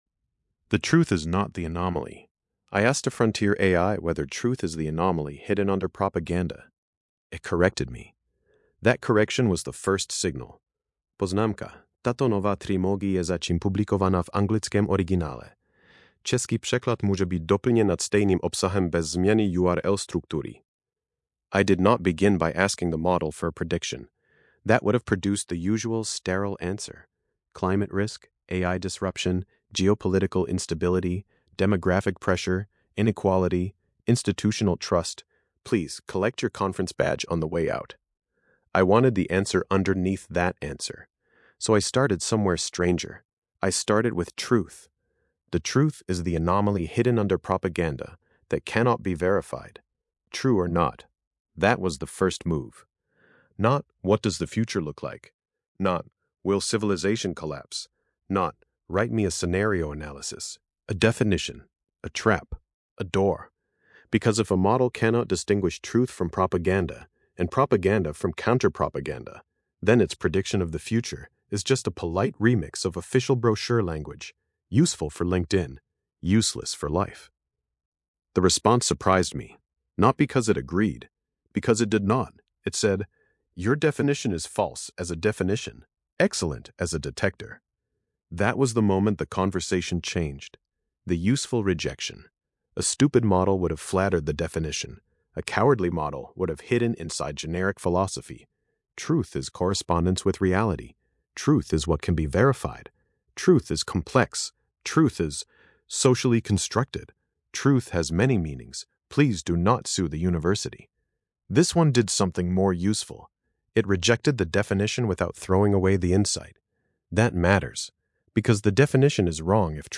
Podcastová audio verze této eseje, vytvořená pomocí Grok Voice API.